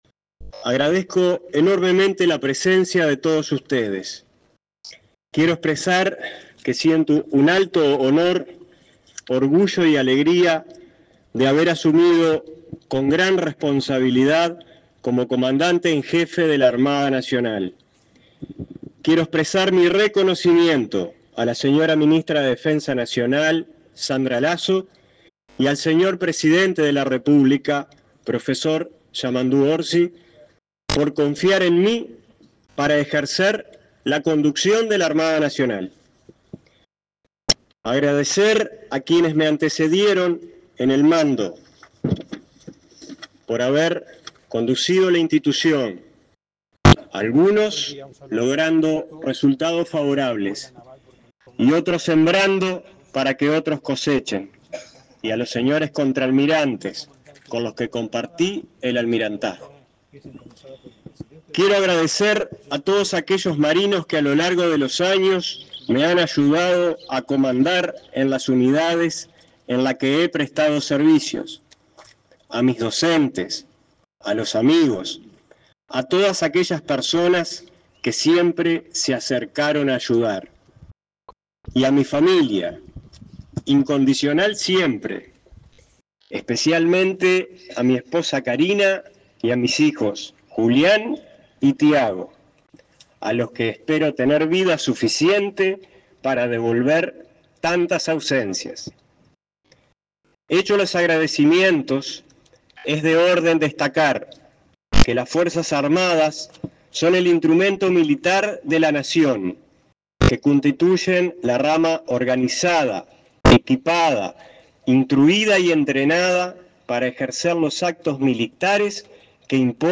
El comandante en jefe de la Armada Nacional, José Luis Elizondo, se expresó durante la ceremonia de su asunción.